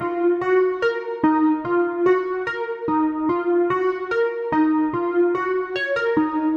硬套鼓循环146
Tag: 146 bpm Trap Loops Drum Loops 1.11 MB wav Key : D